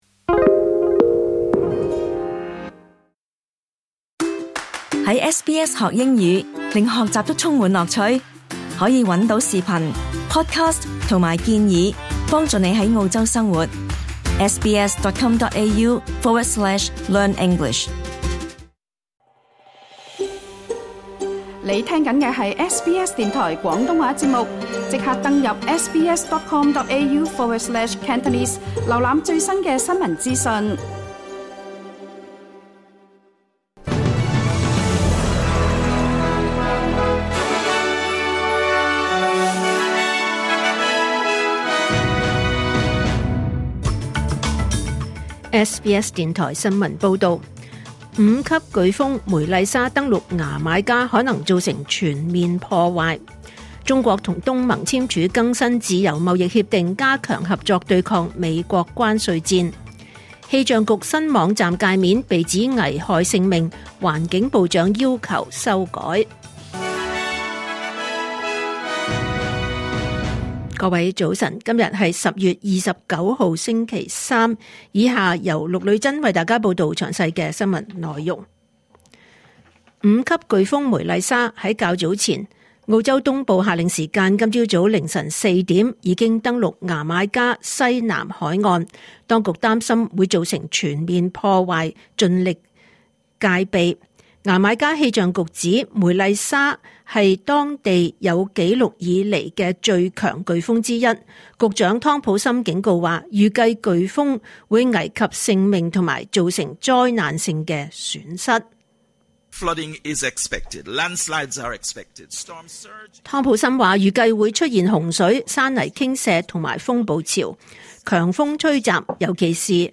2025年10月29日SBS廣東話節目九點半新聞報道。